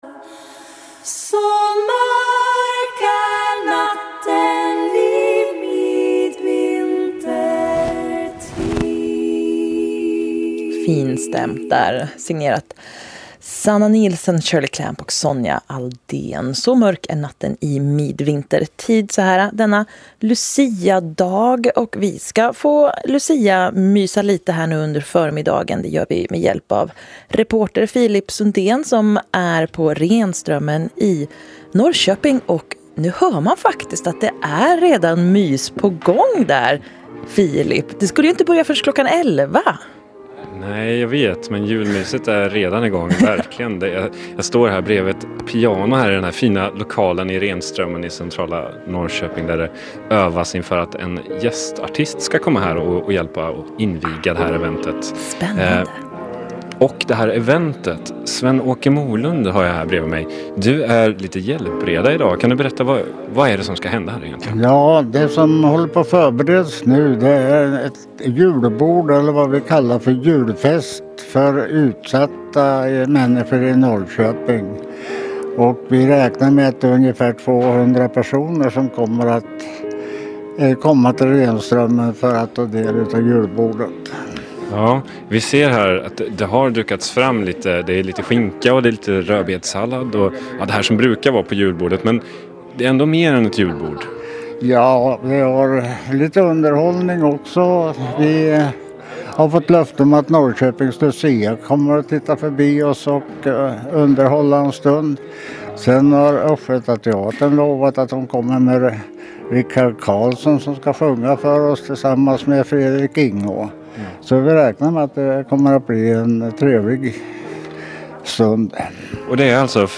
Tillf�llig l�nk till kompri,erad fil med Radio �sterg�ttlands bevakning av julfesten. Julfest 2018\Omsorgsjuls julfest.MP3
Omsorgsjuls julfest.MP3